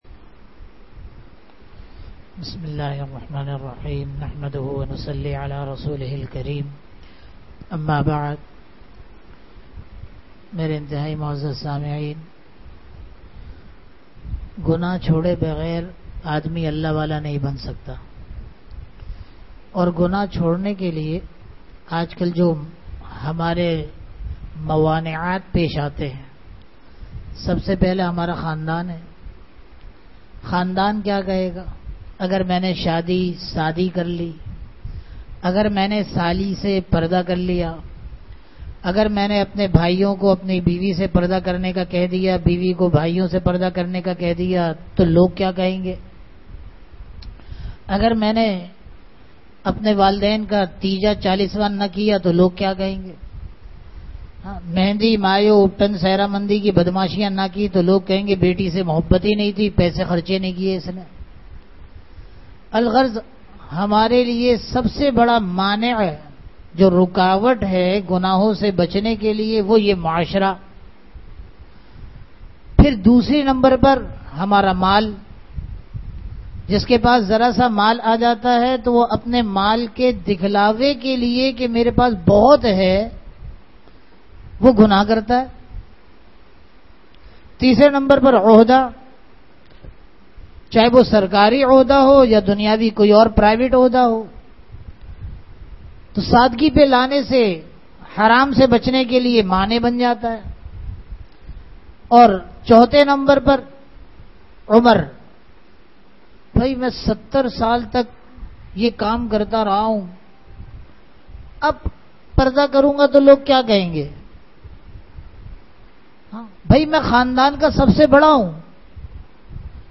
Bayanat
Allah wala banne ke lie gonahon ka chorna zarori (bad namaz e jummah)